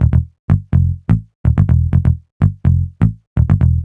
cch_bass_loop_picked_125_Am.wav